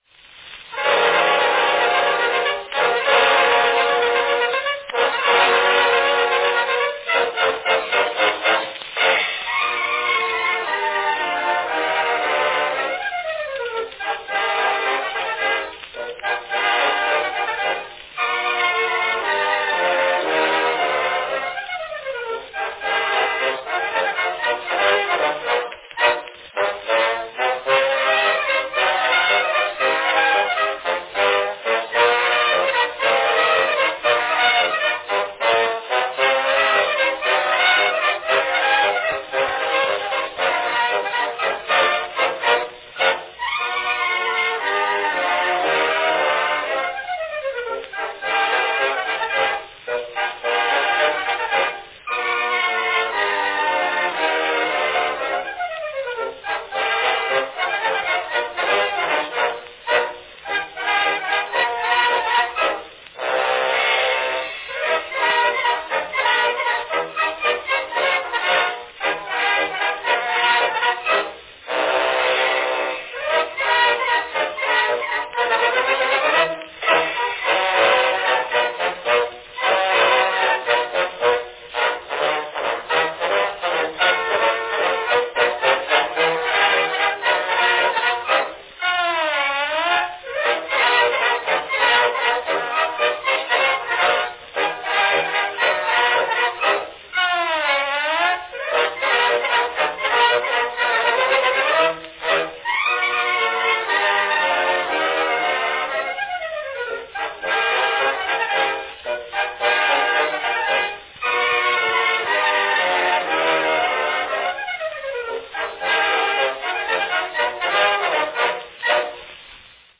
From 1909, a snappy ragtime performed by the New York Military Band - Wild Cherry.
Category Band
Performed by New York Military Band
Announcement None
A fine recording of a hit ragtime tune - representing the "cutting edge" ragtime and proto-jazz sounds of popular music during this period.
It is one of the brightest and sprightliest rag-time pieces brought out in many a day, and it makes an instant hit wherever played.